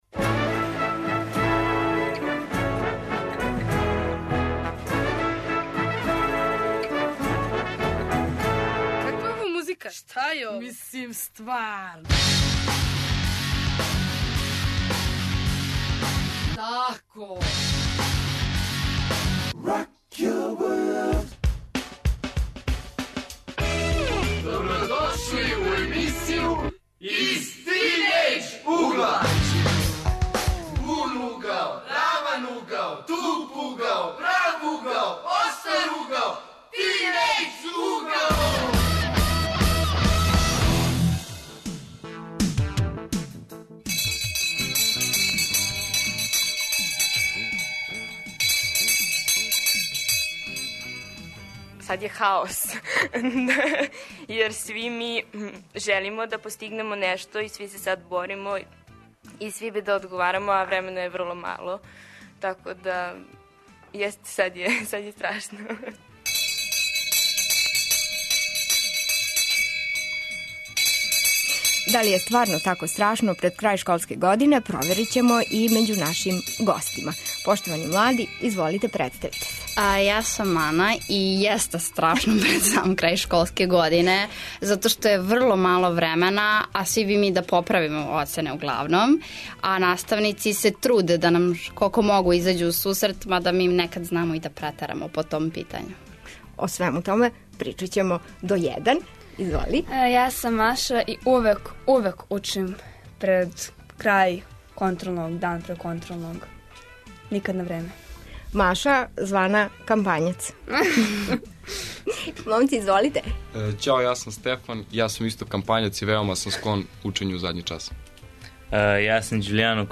У емисији ћете чути репортажу из Лесковца и сазнати како уче лесковачки средњошколци, а чућете и мишљење педагога.